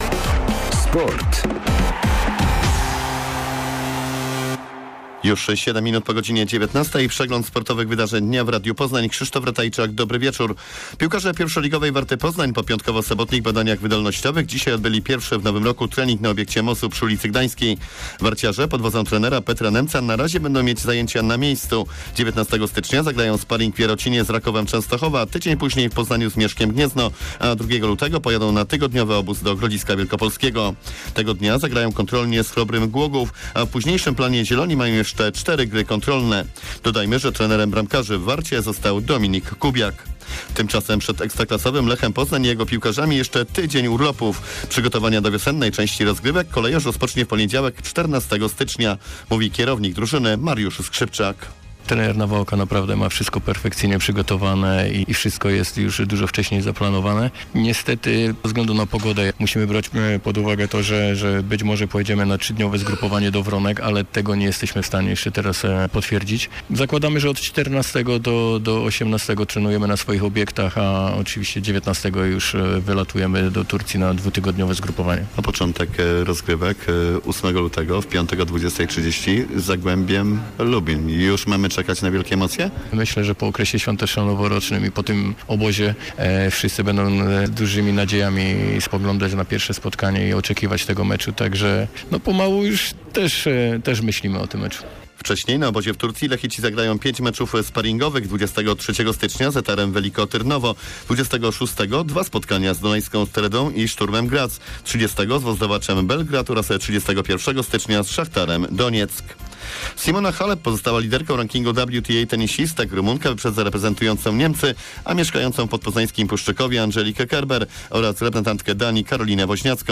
07.01 serwis sportowy godz. 19:05